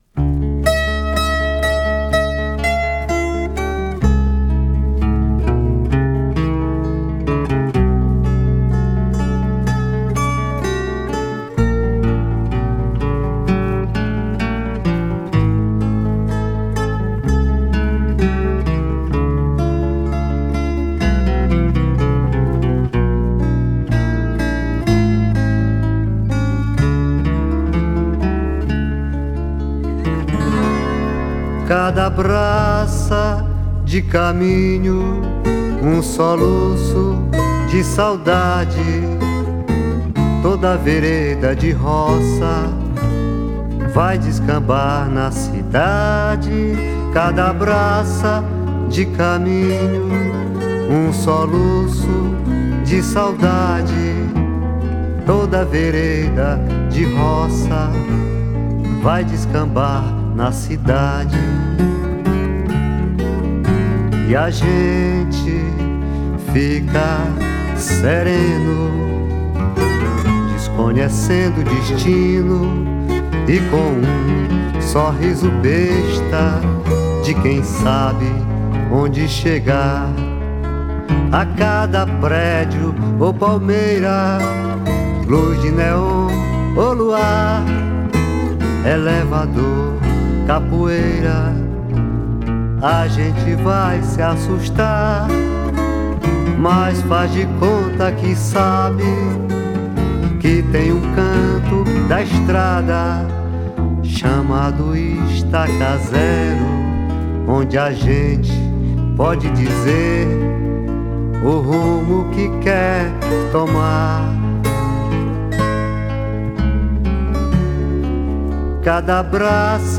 1620   03:48:00   Faixa:     Canção